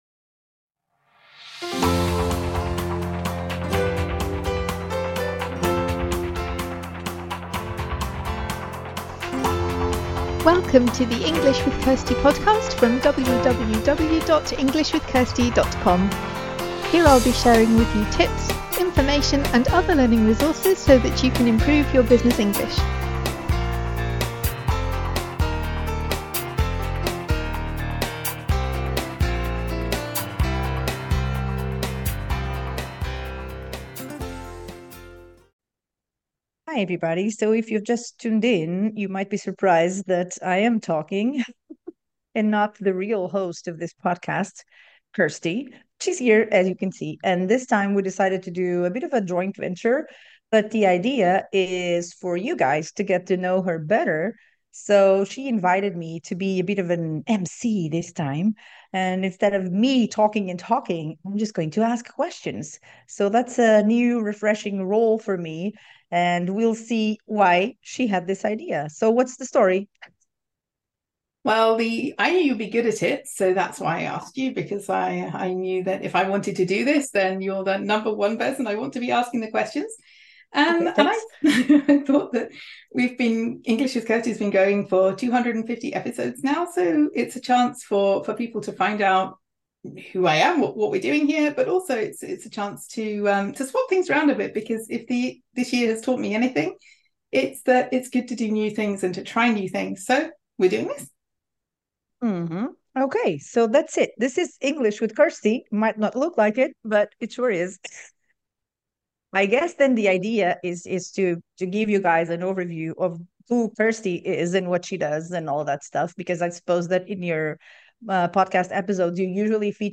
We have spontaneous, real conversations!